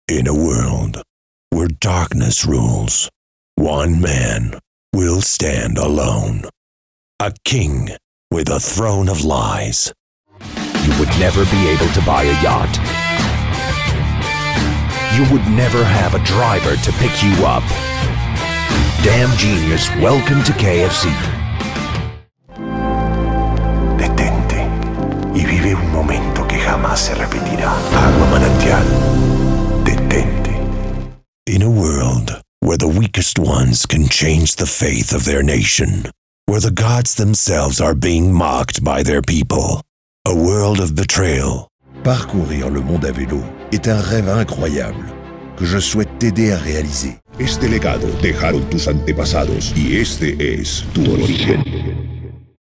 Locutores de Podcasts
Spanish (Latin American)
Adult (30-50) | Older Sound (50+)